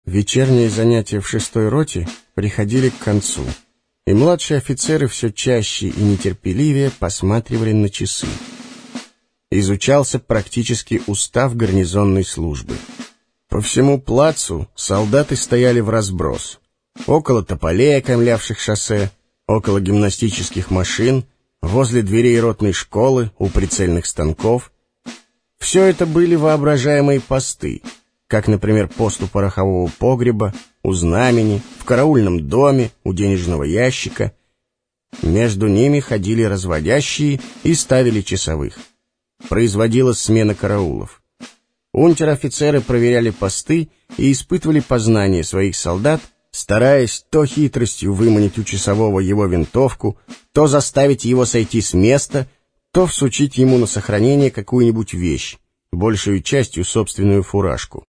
Аудиокнига Поединок | Библиотека аудиокниг